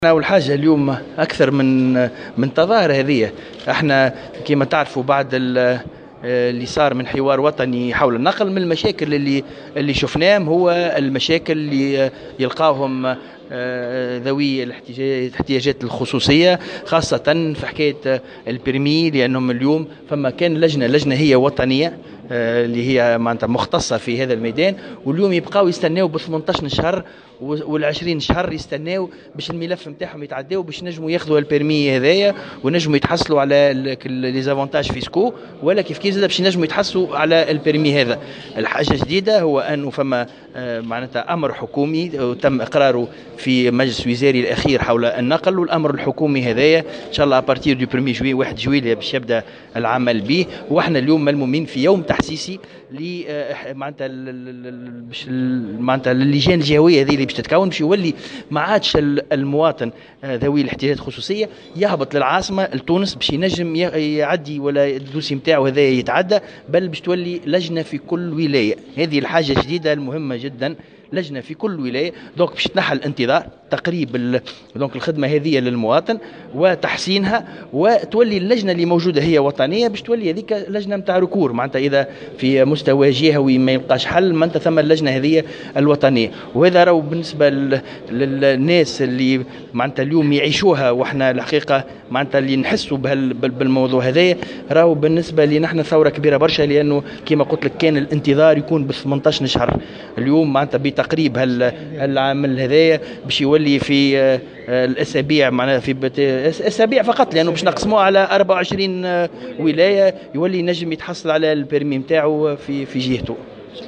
وأوضح في تصريح لمراسلة "الجوهرة أف أم" على هامش يوم تحسيسي حول هذا القرار الذي يدخل حيز التطبيق يوم 1 جويلية 2019، أن الأمر الحكومي يمكّن من إحداث لجنة جهويّة في كل ولاية مكلّفة بالنّظر في ملفات المواطنين ذوي الاحتياجات الخصوصية الرّاغبين في الحصول على رخصة سياقة، بدلا من الاقتصار على لجنة وطنية واحدة مما ادى إلى تعطيل مصالح المواطنين بالانتظار نحو 18 شهرا ليصبح بالإمكان مستقبلا الحصول على رخصة سياقة في غضون أسابيع بعد احداث هذه اللجان الجهوية.